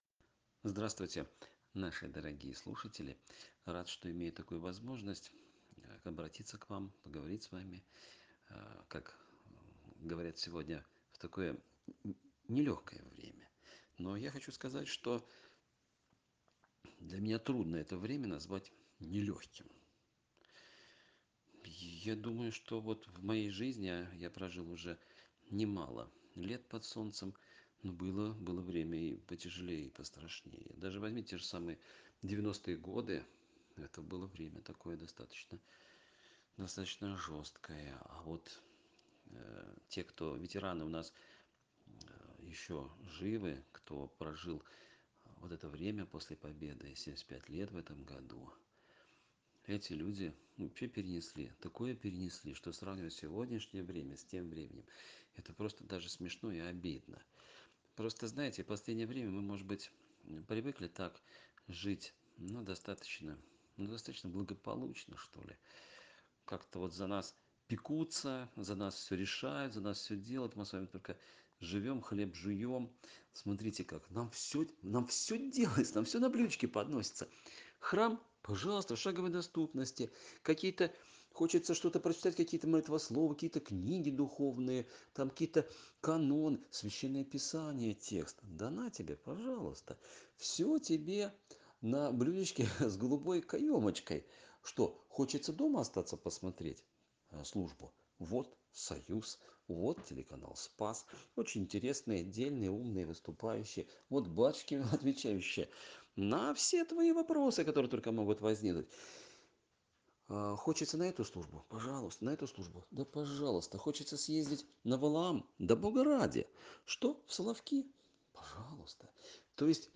В разгар эпидемии, когда многие люди не имеют возможности ходить в храм, епископы и священники записали проповеди для верующих, которые проводят дни Великого поста вдали от храма.